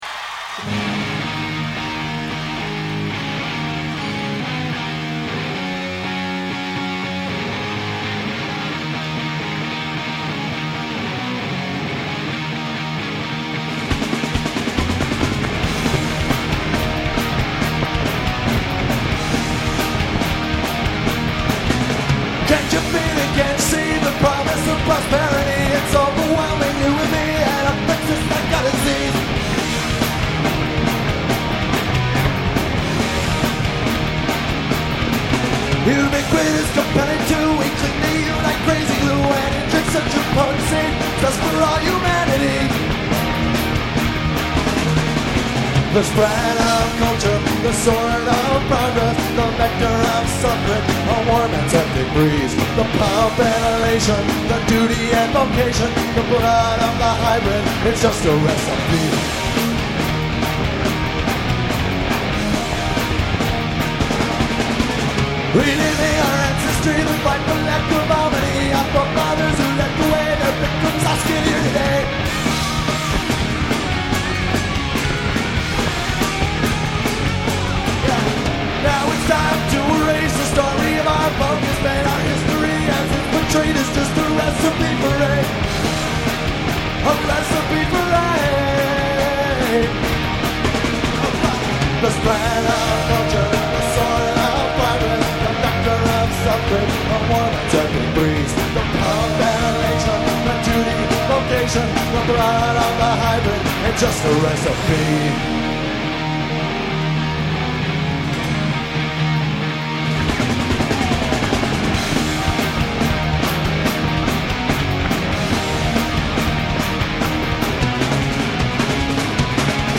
Tracks 2,3,6 and 7: live from KROQ Acoustic Xmas line